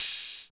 64 OP HAT.wav